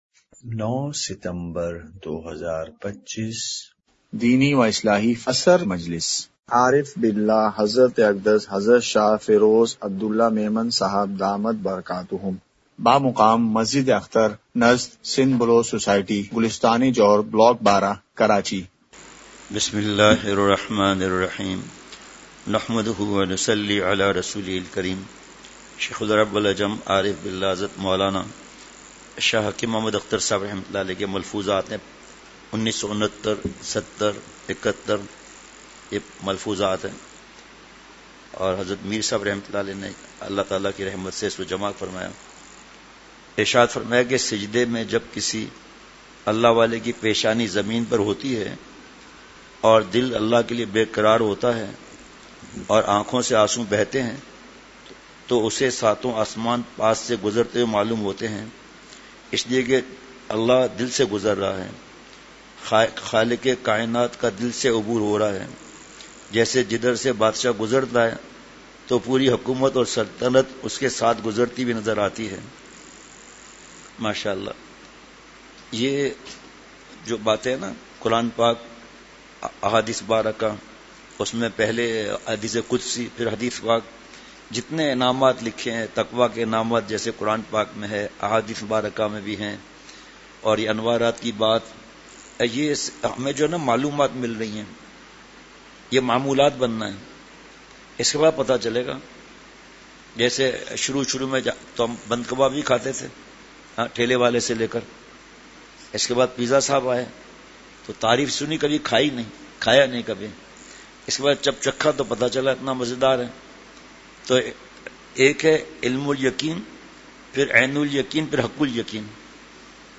عصر مجلس ۹ / ستمبر ۲۵ء:سر پر ہر وقت مربی کا سایہ رکھنا چاہیے !
*مقام:مسجد اختر نزد سندھ بلوچ سوسائٹی گلستانِ جوہر کراچی*